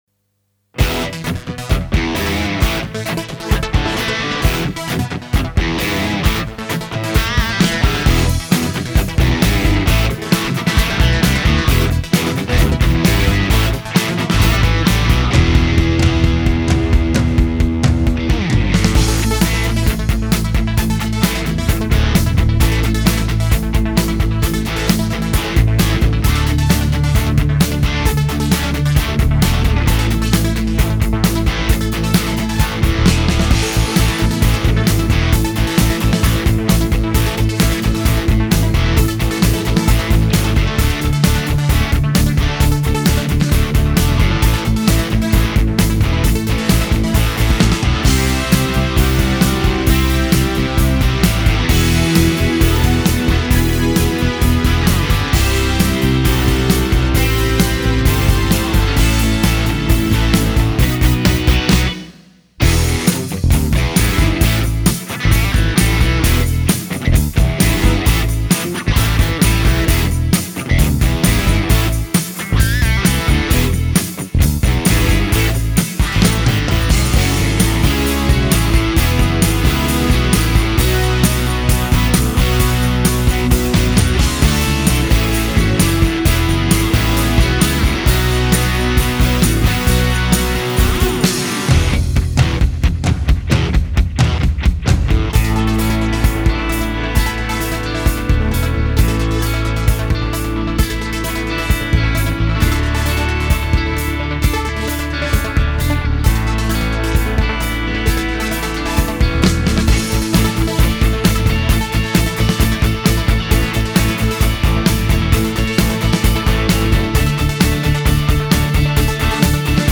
rockig, fröhlich, kräftig
Tempo 132 4/4 E